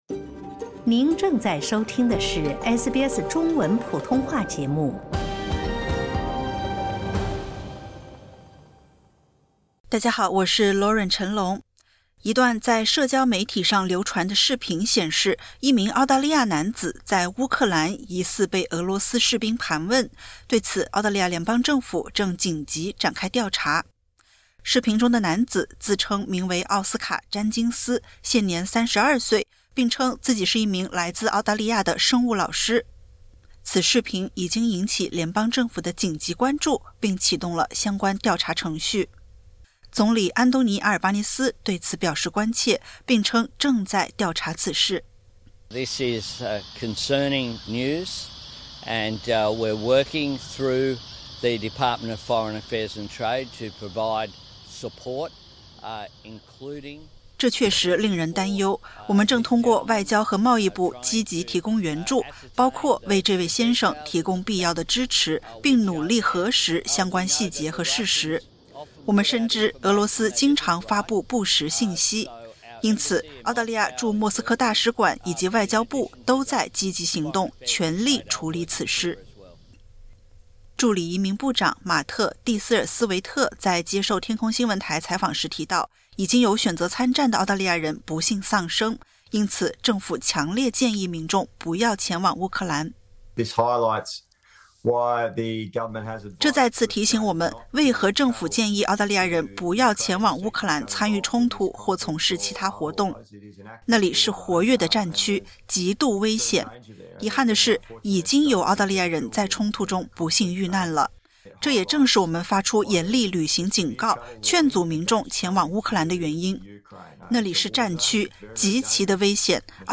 一段在社交媒体上流传的视频显示，一名澳大利亚男子在乌克兰疑似被俄罗斯士兵盘问，对此，澳大利亚联邦政府正紧急展开调查。点击 ▶ 收听完整报道。